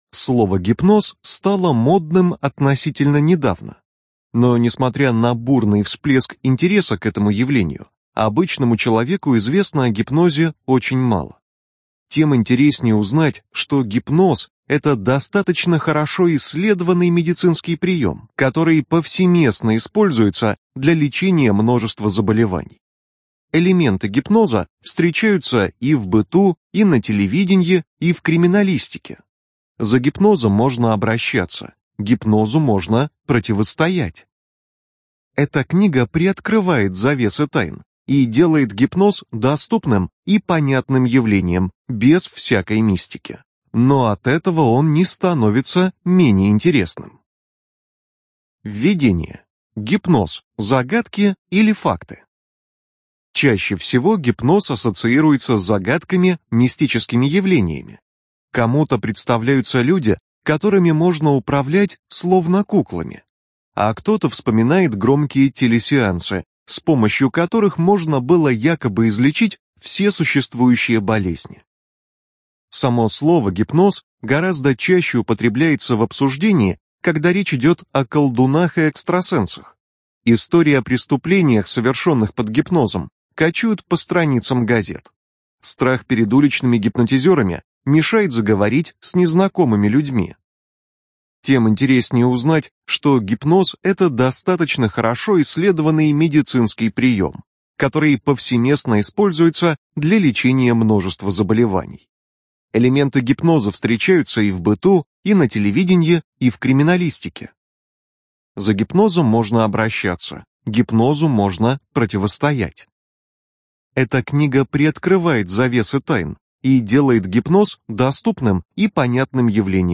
Информация о книге Гипноз. Как использовать и противостоять (Библиотека ЛОГОС)